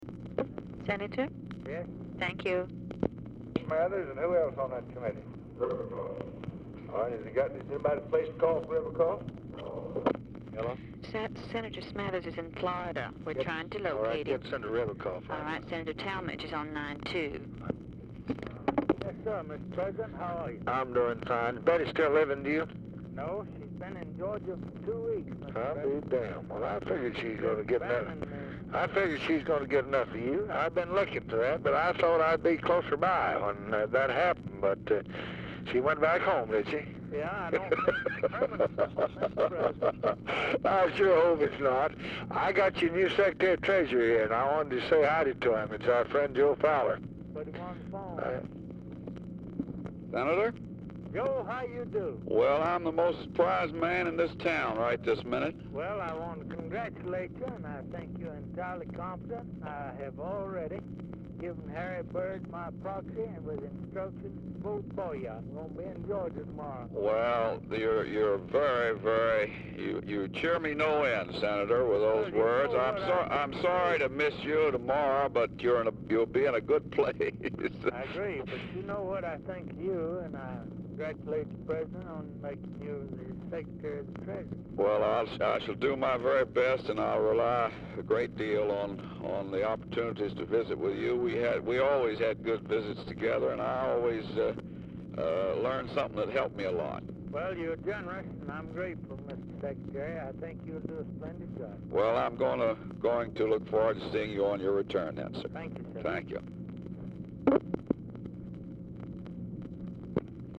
Telephone conversation # 7113, sound recording, LBJ and HERMAN TALMADGE
BRIEF OFFICE CONVERSATION ABOUT PLACING ADDITIONAL CALLS PRECEDES CALL
Format Dictation belt